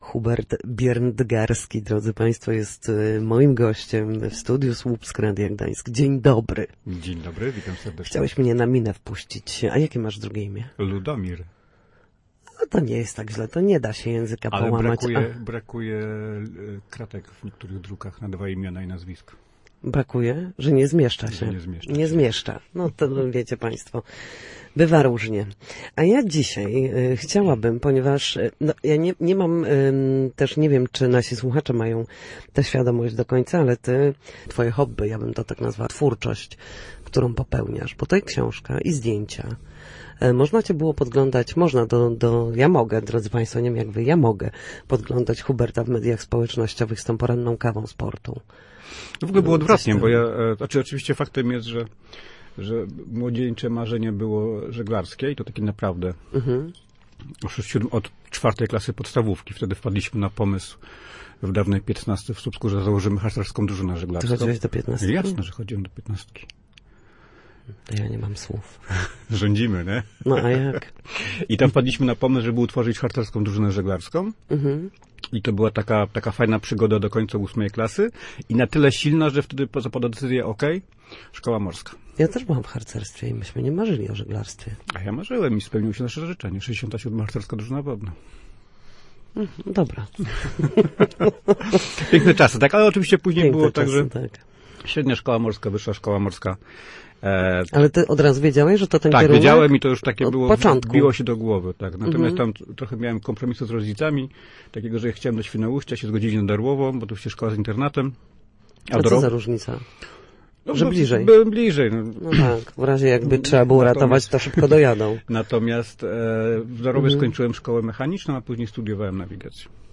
zapraszał w Studiu Słupsk